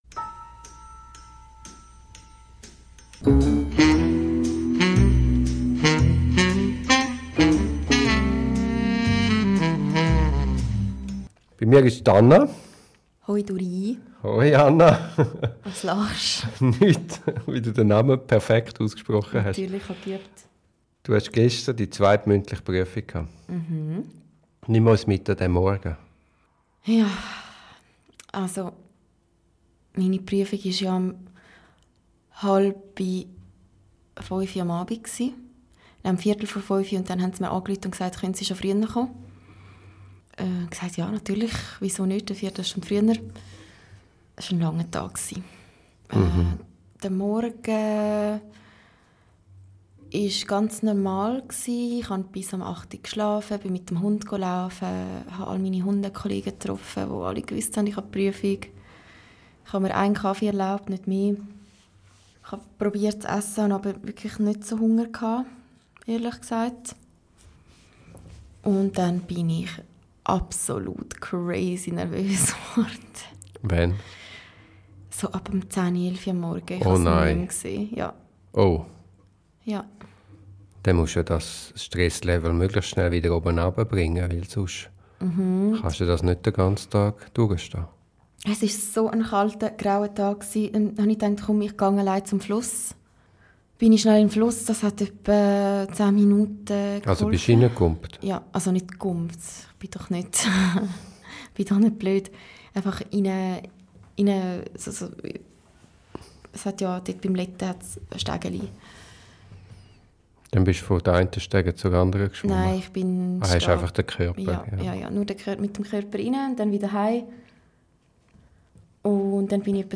Es ist ein Gespräch über extreme Nervosität, Selbstkontrolle unter Hochdruck – und über den Moment, in dem alles kippt: von Angst zu Klarheit, von Zweifel zu Ruhe.